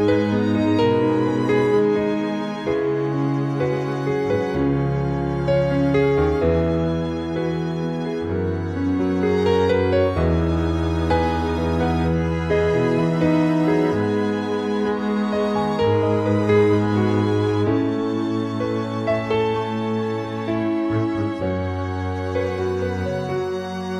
Up 4 Semitones For Female